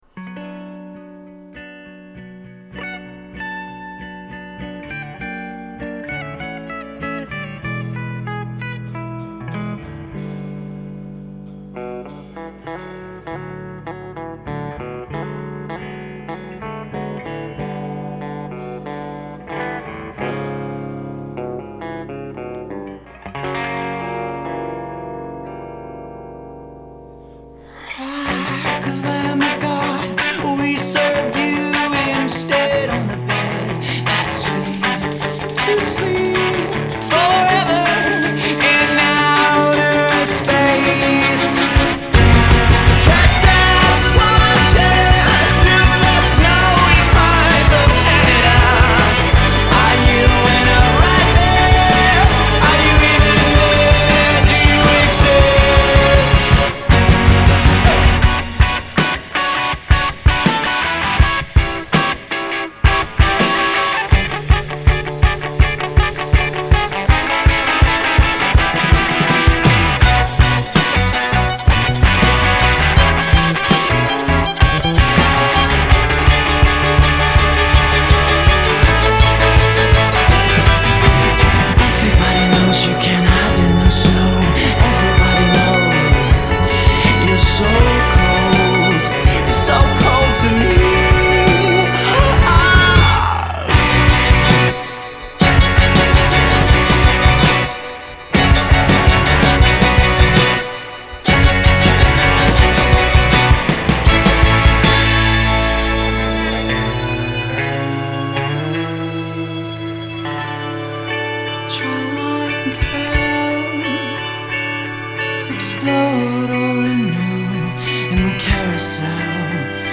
This album sounds like a very complicated demo tape.